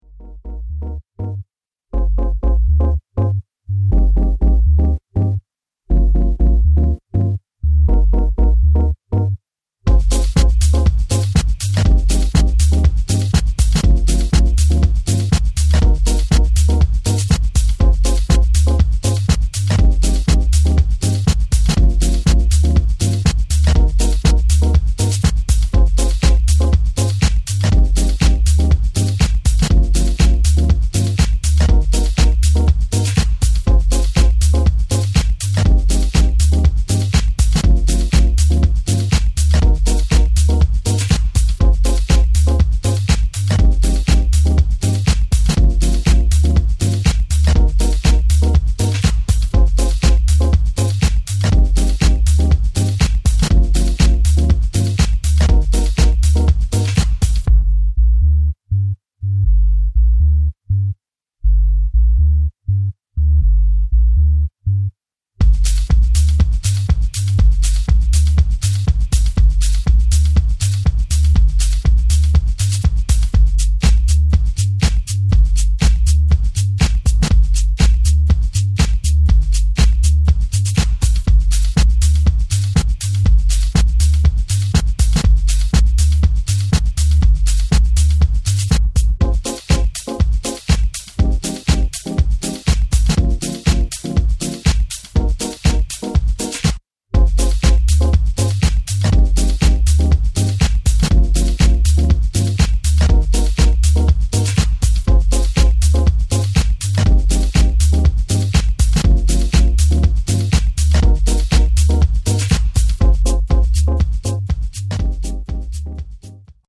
timeless deep house